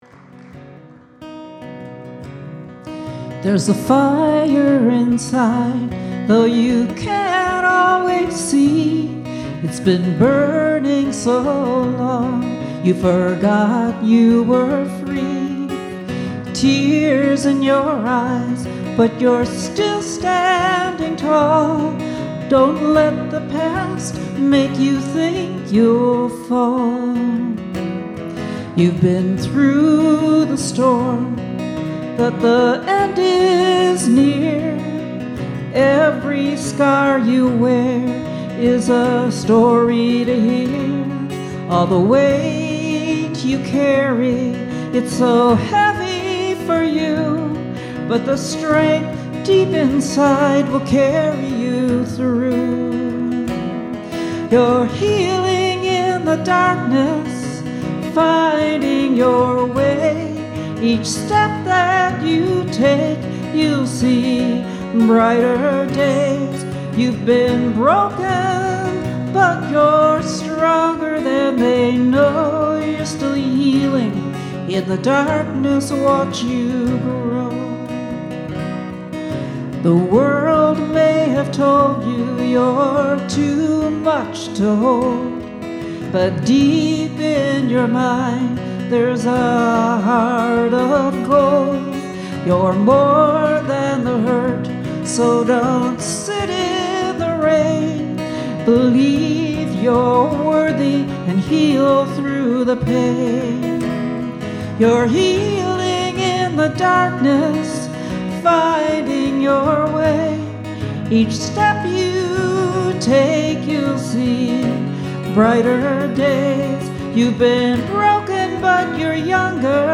At our last concert